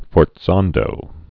(fôrt-sändō)